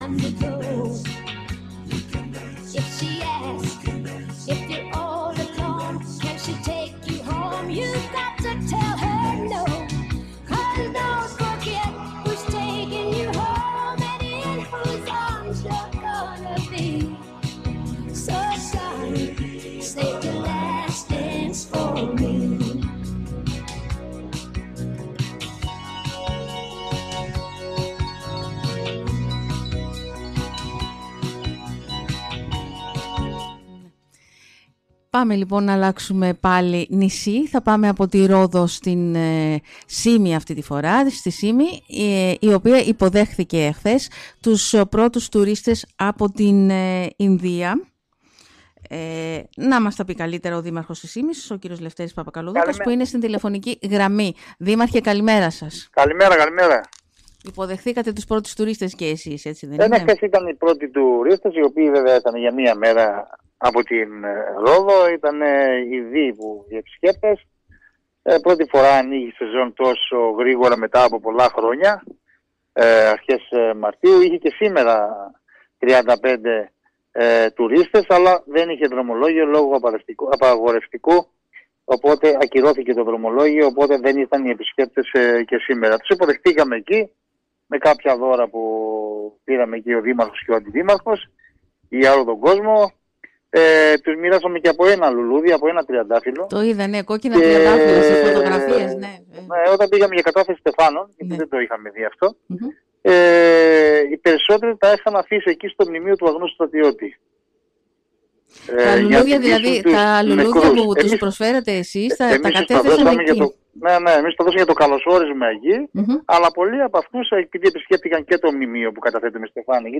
Στην έναρξη της τουριστικής σεζόν και για την Σύμη αναφέρθηκε σήμερα μιλώντας στον Sky ο δήμαρχος του νησιού κ. Λευτέρης Παπακαλοδούκας.
Το ηχητικό της συνέντευξης :